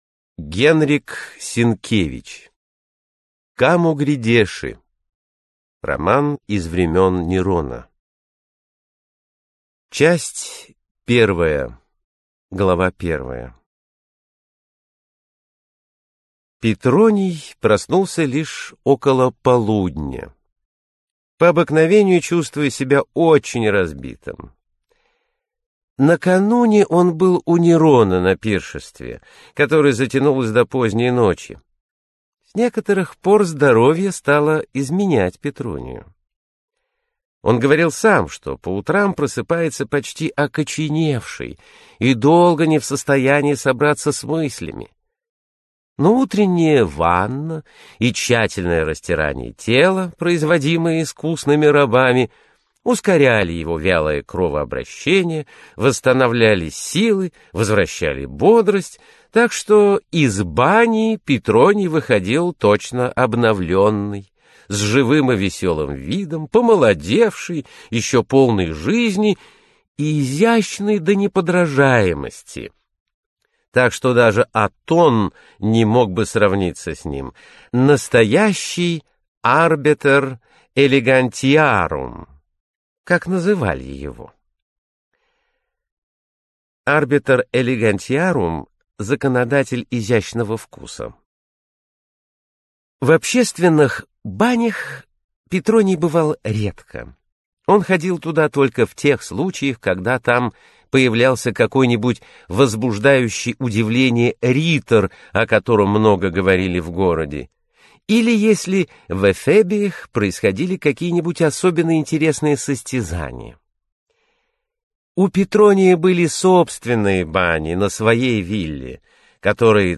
Аудиокнига Камо грядеши - купить, скачать и слушать онлайн | КнигоПоиск